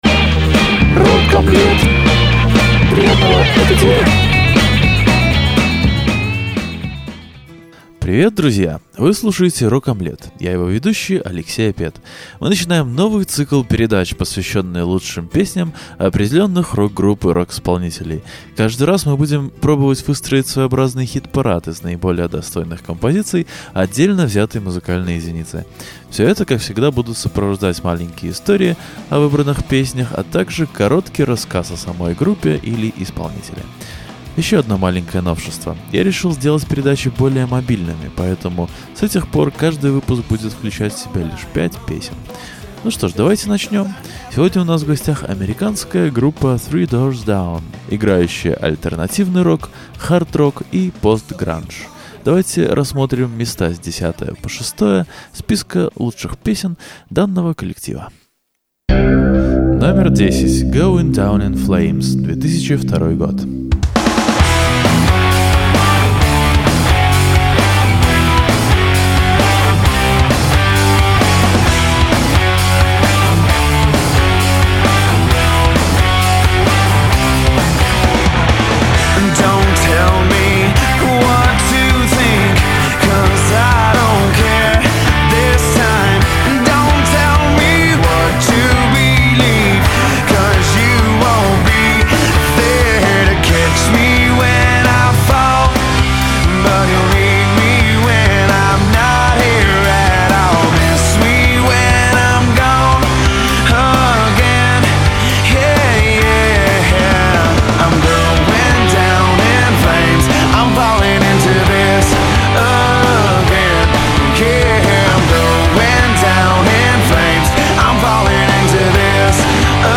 Сегодня у нас в гостях американская группа 3 Doors Down, играющая альтернативный рок, хард-рок и пост-гранж.